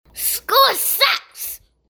L-SCHOOL-SUCKS-A-KID.mp3